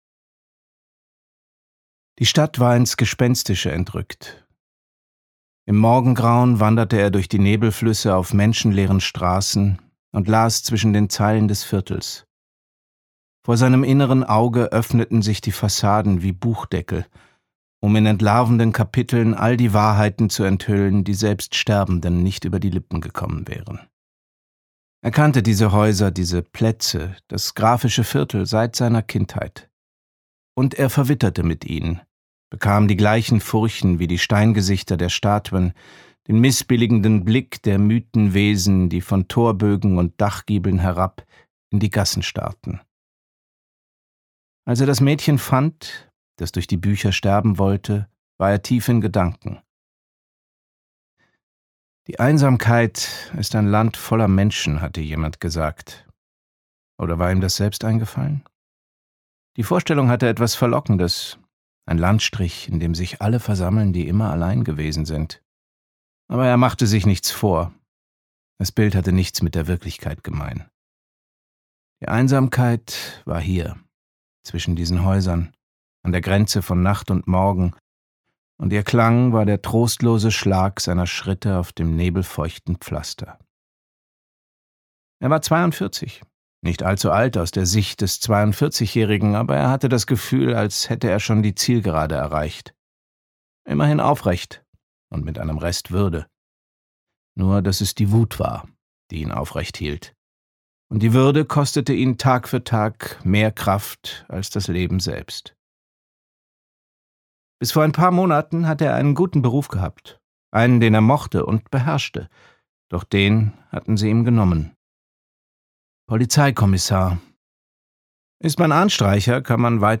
Interpreten: Luise Helm, Johann von Bülow
Die Geschichte wird immer abwechselnd vom Kommissar bzw. der Lektorin erzählt.
Die Story fliest gut und der Sprecher und die Sprecherin leisten dazu einen nicht unbedeutenden Beitrag. Sie packen genug Emotionen in ihre Stimmen, um einen davon zu überzeugen, dass man das gerade hautnah erlebt.